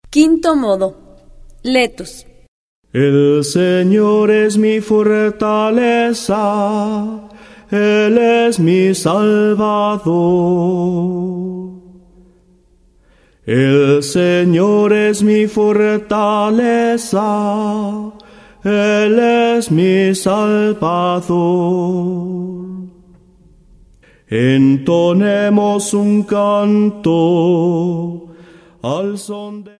06 Quinto modo gregoriano.